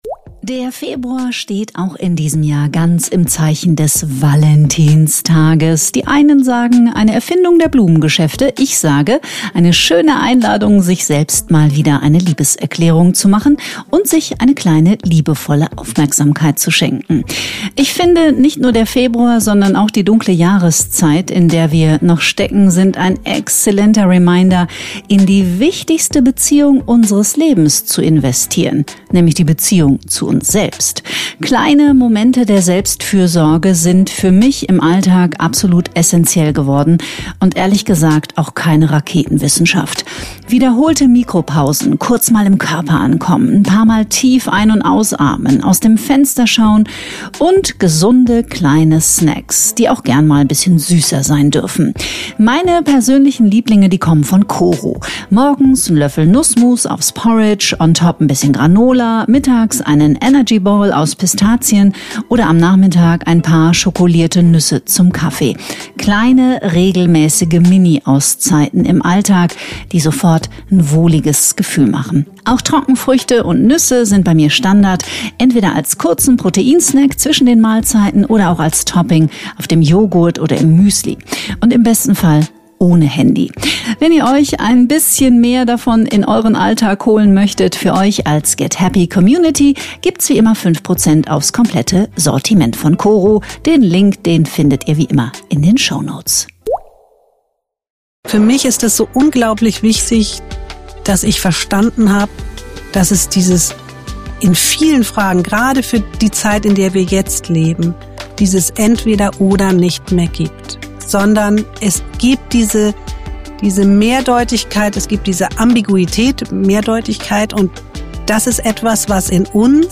im Gespräch über schwierige Zeiten und wie wir uns besser in ihnen zurecht finden: durch Ambivalenzkompetenz!